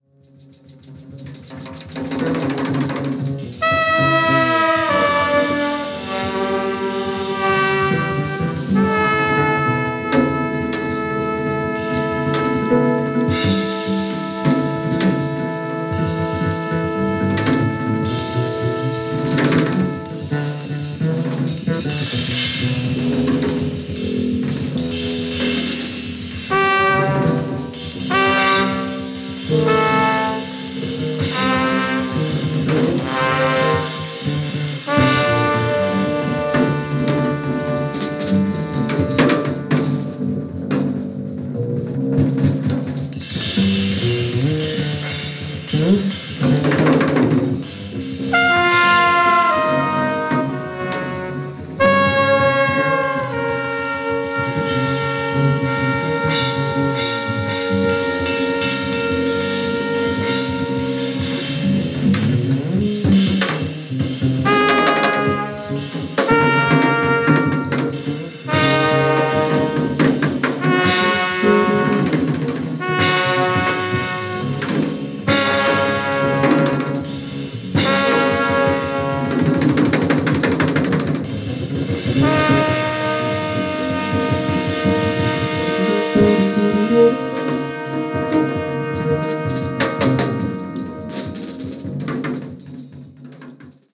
ワイルドなメンバーが揃うとこうなりますね！！！